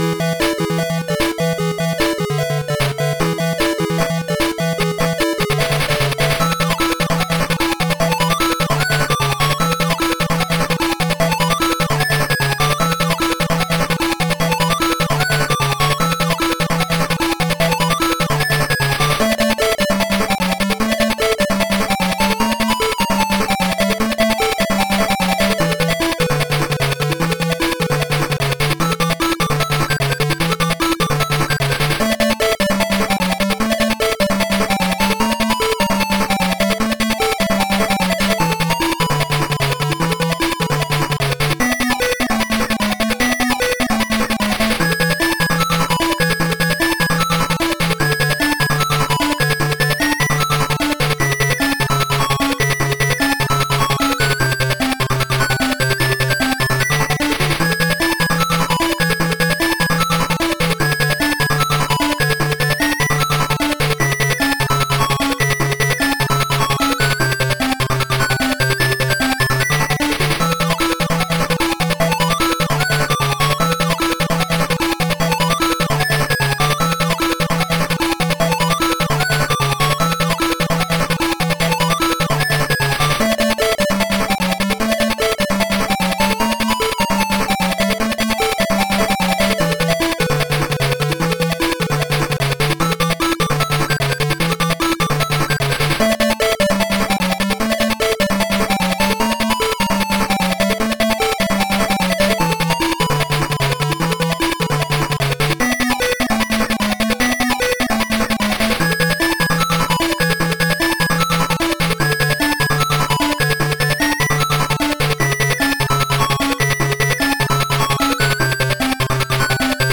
IT GOES SO HARD
Instrumental